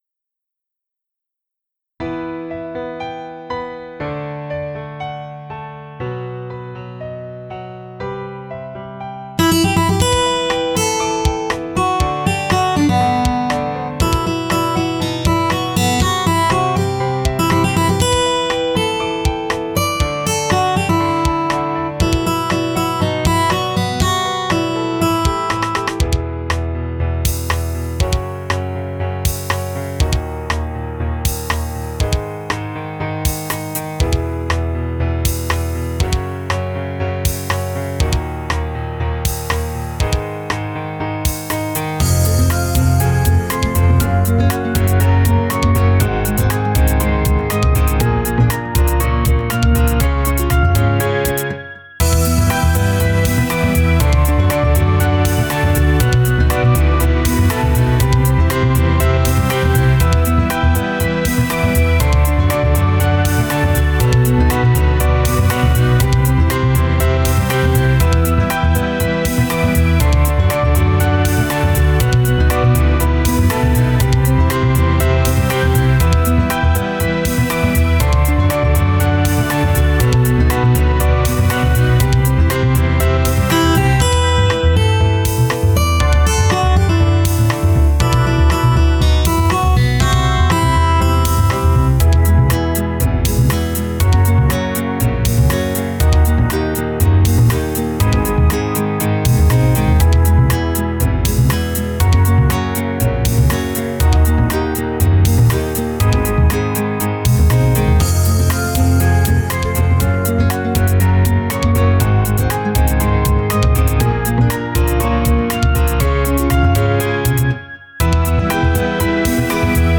BPM：96